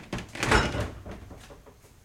fridgeOpen.wav